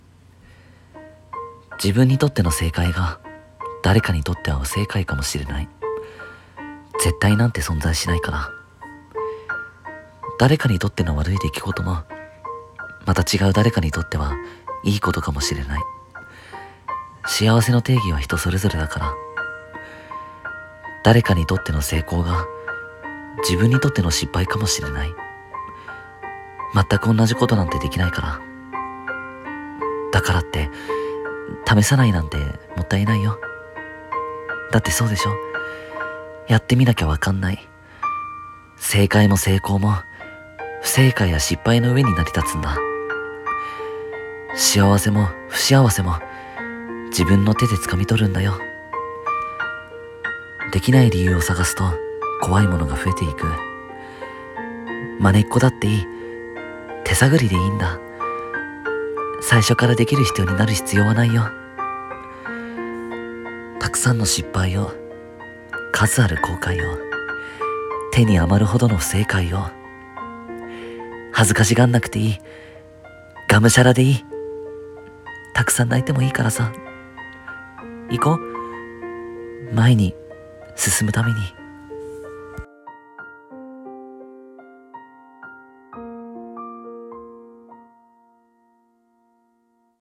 【声劇台本】失敗してもいいんだよ。